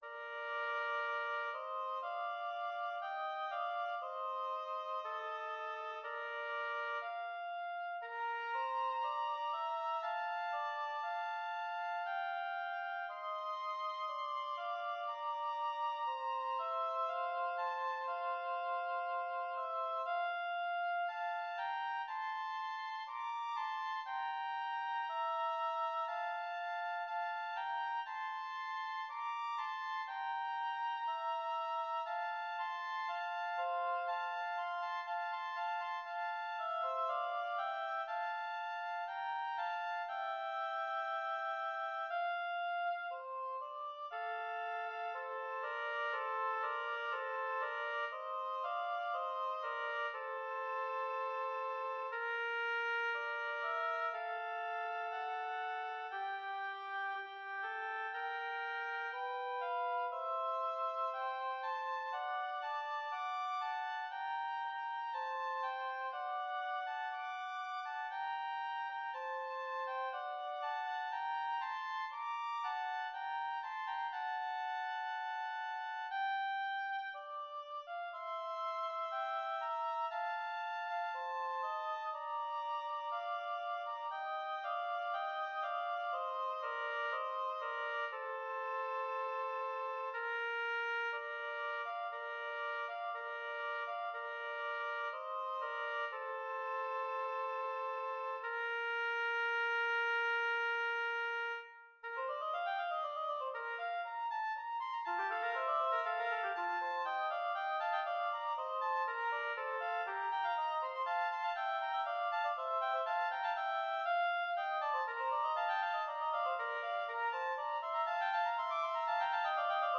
Intermediate oboe duet
Instrumentation: oboe duet